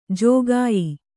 ♪ jōgāyi